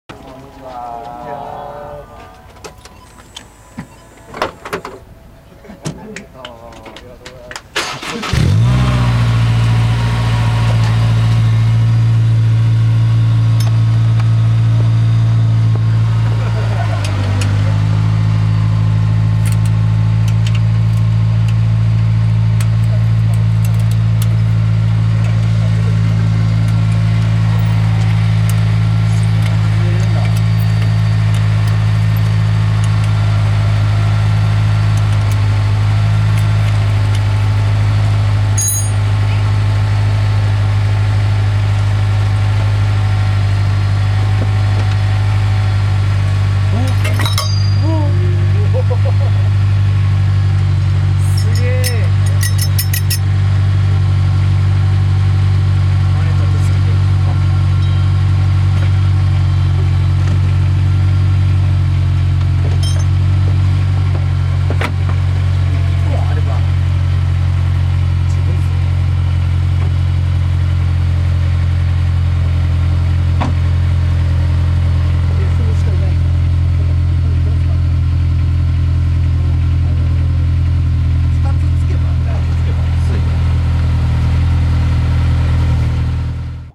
(今回はS2000マフラーサウンド録音オフだよ〜）
マイク：業務用ガンマイク
金属音のリアリティがナカナカですよ♪